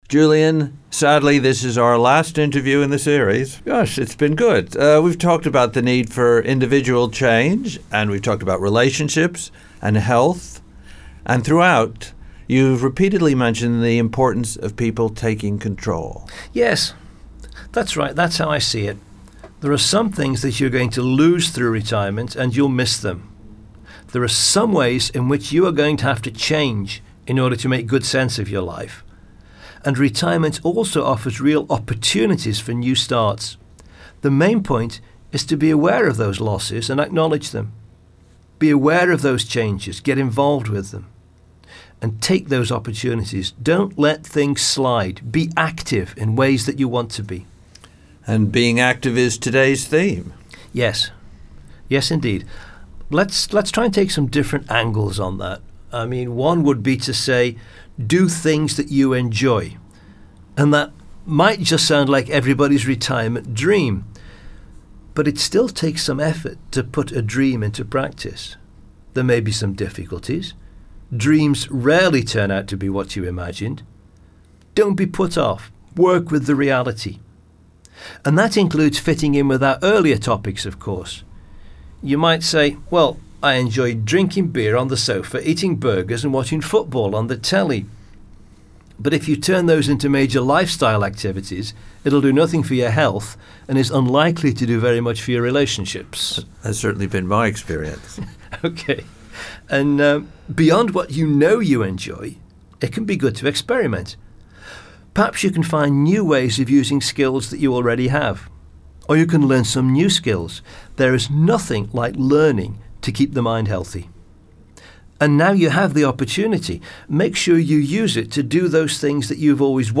There are 4 short interviews.
04_-_Interview_4.mp3